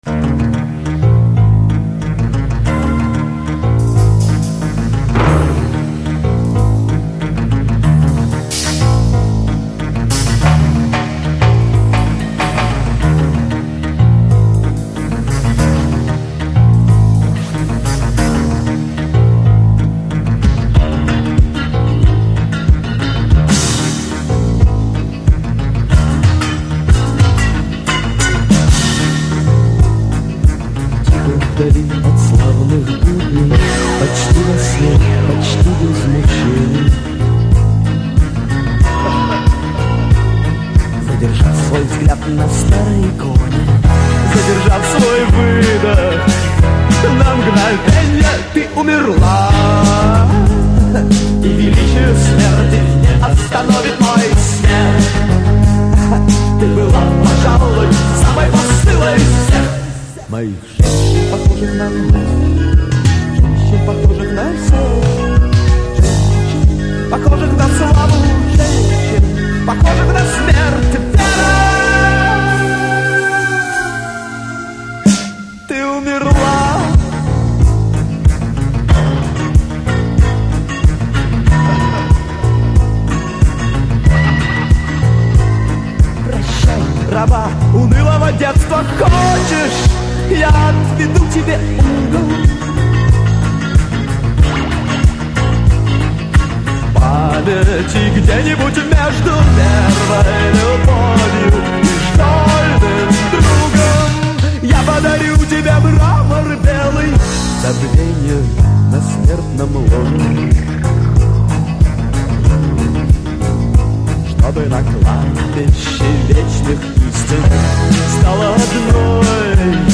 ничего удивительного нет, драйв тот же,
Настоящий буйный, ага.